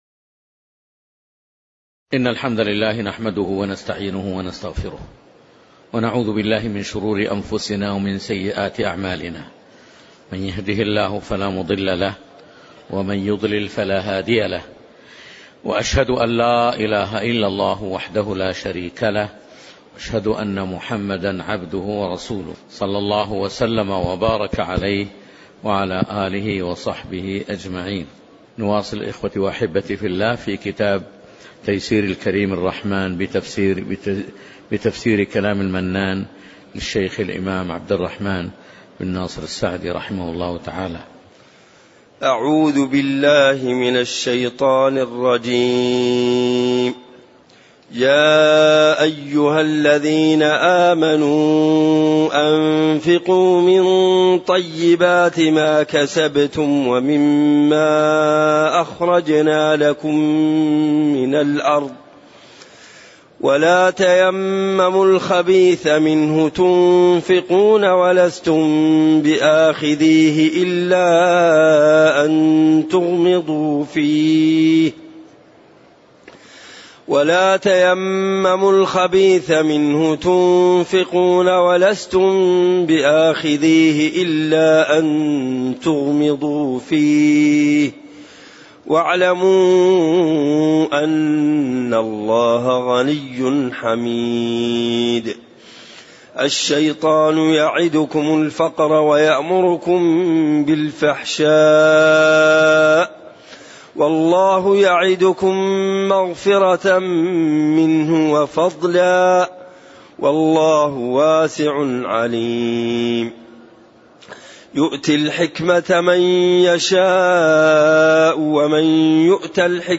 تاريخ النشر ٤ شعبان ١٤٣٩ هـ المكان: المسجد النبوي الشيخ